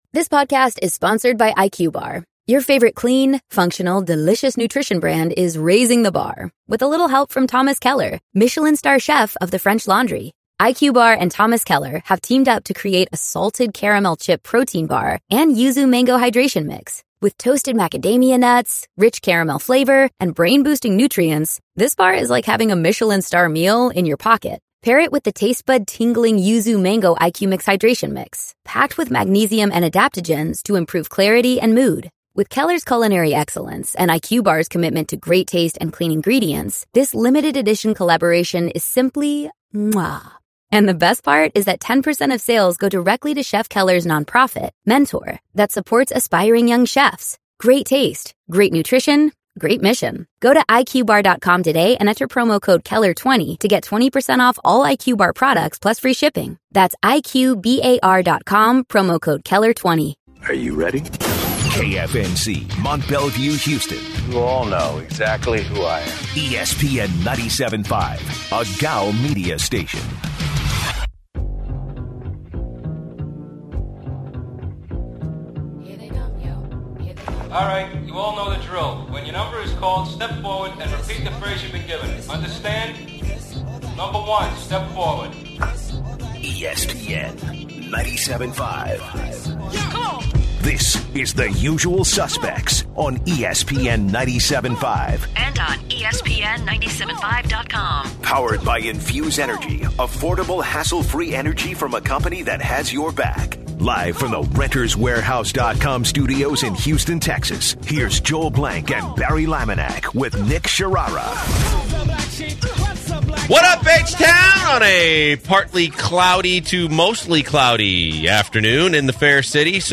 They also answer some calls from listeners for more Rockets talk.